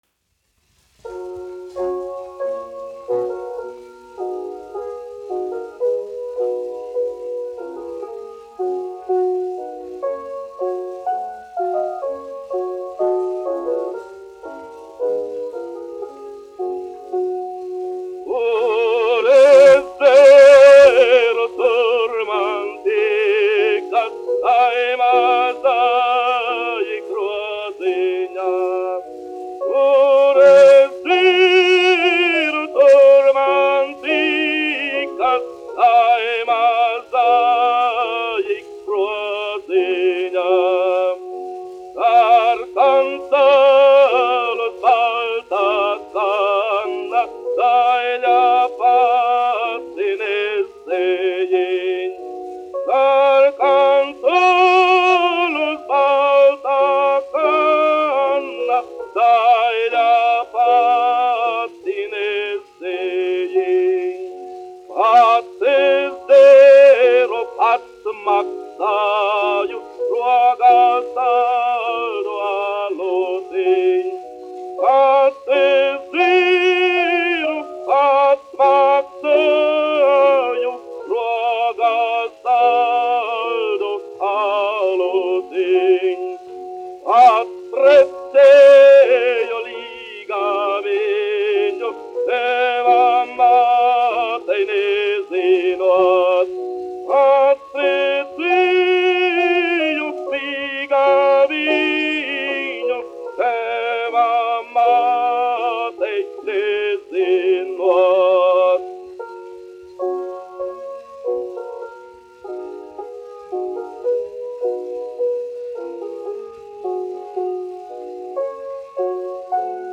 Jāzeps Vītols, 1863-1948, aranžētājs
1 skpl. : analogs, 78 apgr/min, mono ; 25 cm
Latviešu tautasdziesmas
Skaņuplate